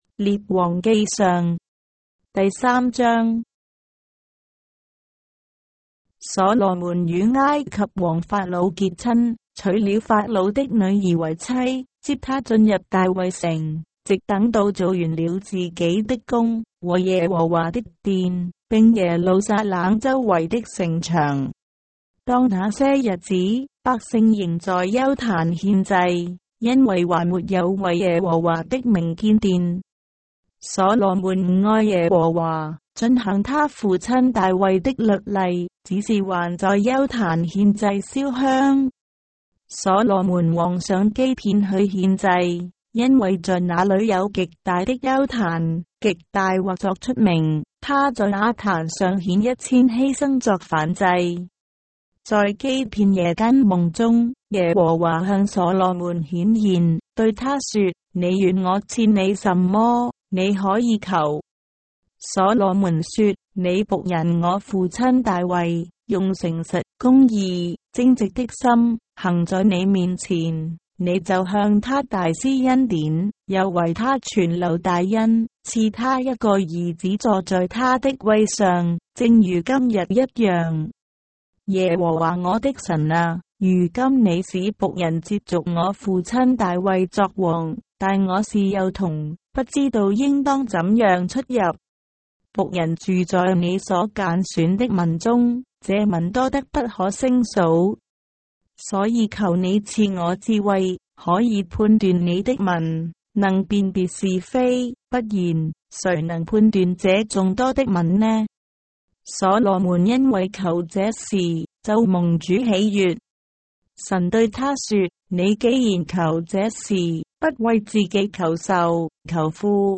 章的聖經在中國的語言，音頻旁白- 1 Kings, chapter 3 of the Holy Bible in Traditional Chinese